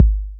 DB - Kick (4).wav